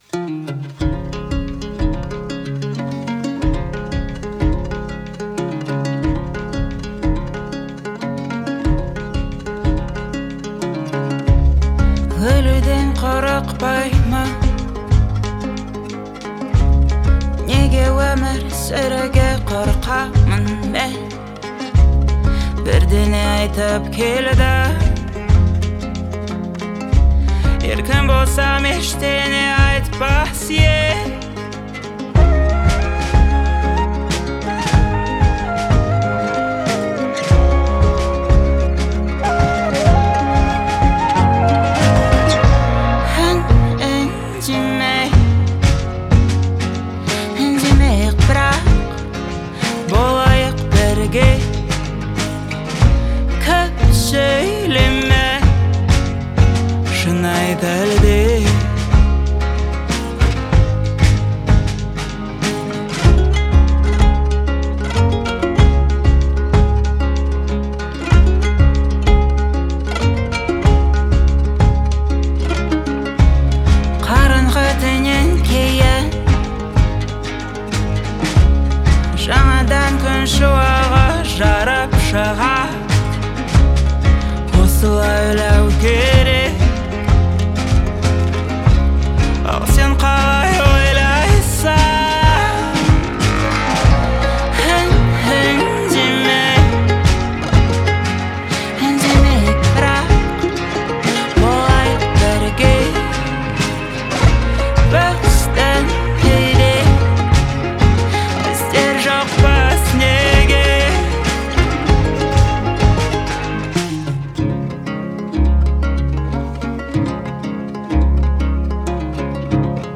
это захватывающая композиция в жанре инди-поп